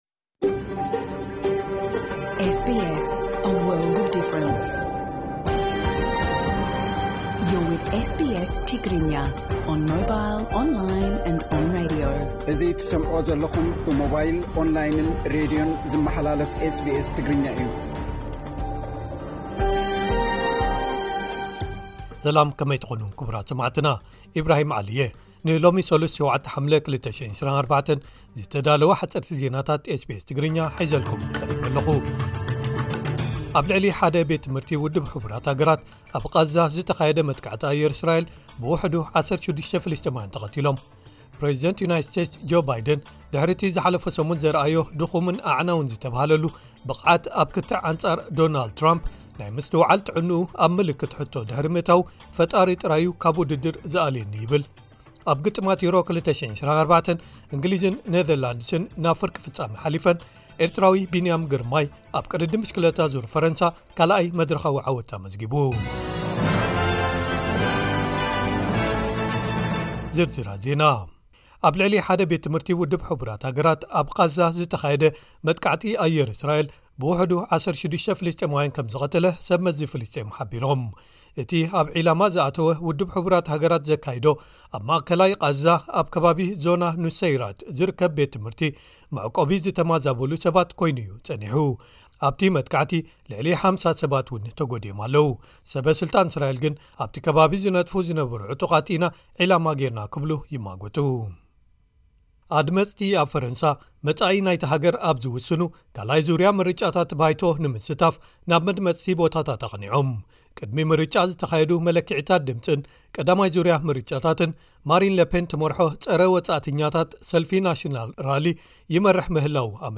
ሓጸርቲ ዜናታት ኤስቢኤስ ትግርኛ